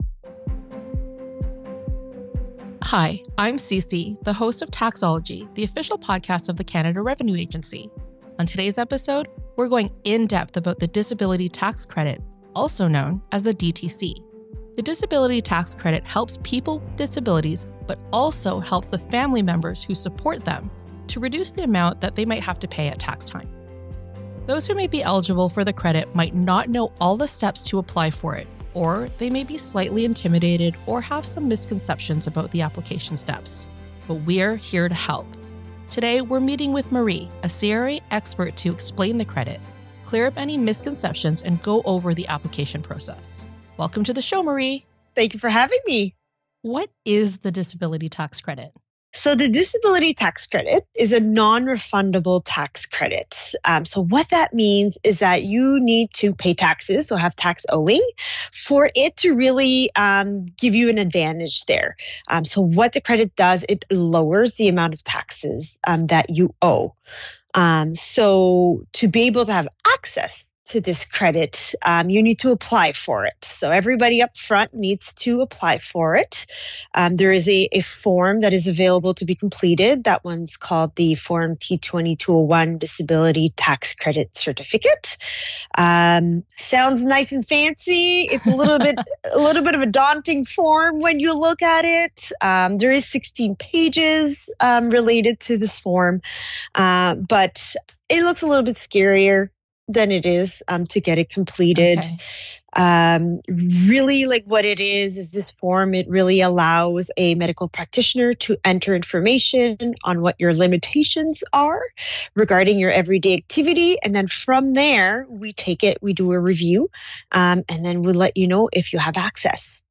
In this episode, we chat with a CRA expert on the DTC, to help you understand the credit and the application process, and to clear up any misconceptions.